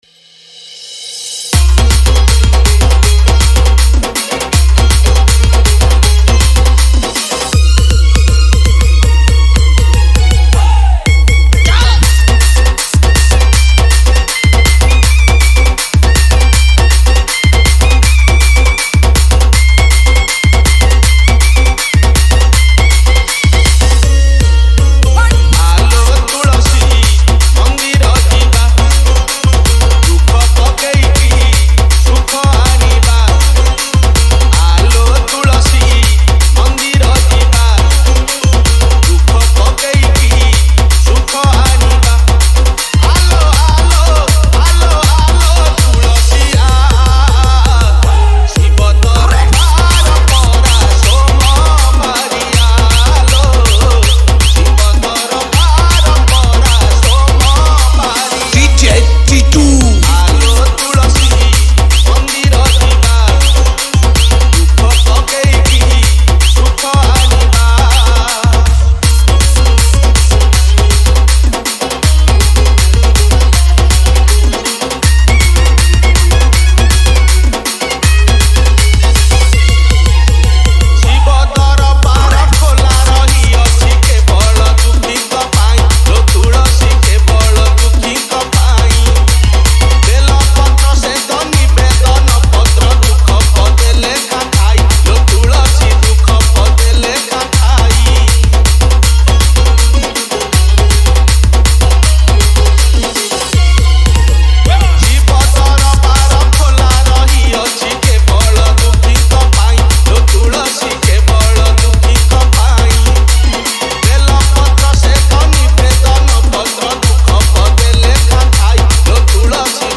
Category:  Odia Bhajan Dj 2025